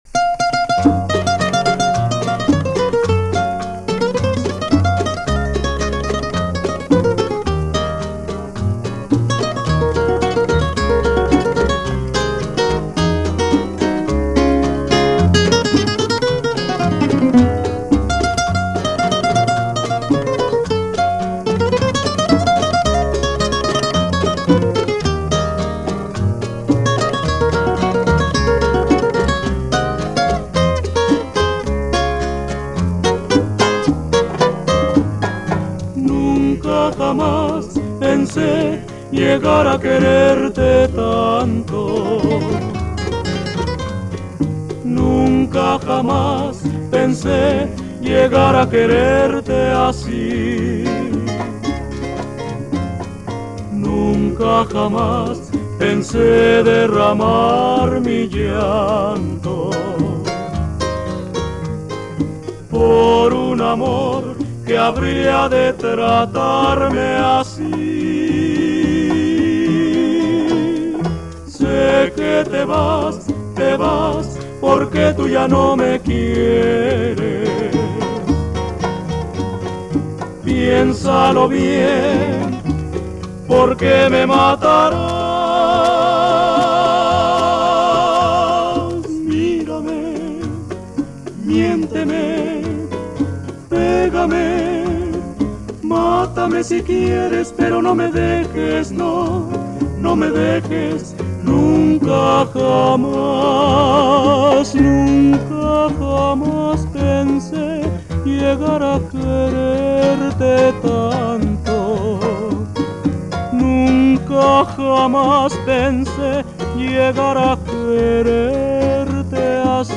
Part of that rich legacy of Latin Music